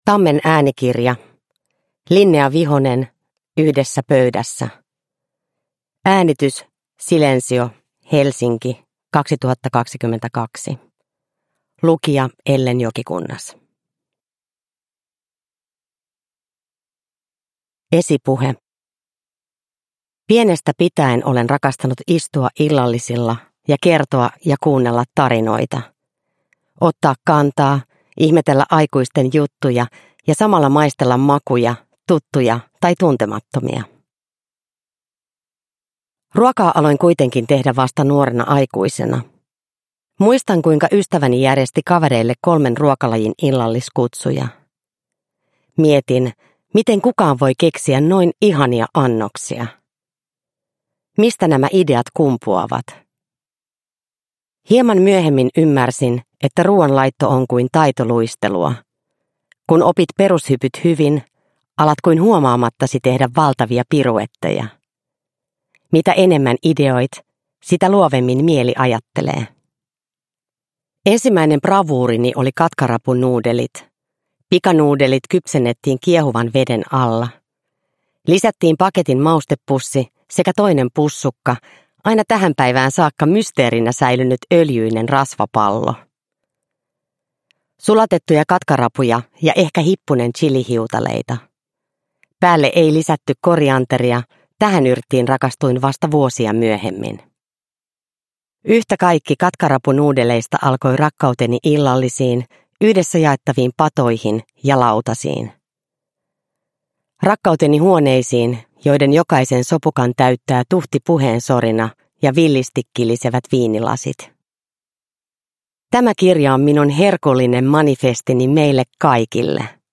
Yhdessä pöydässä – Ljudbok
Uppläsare: Ellen Jokikunnas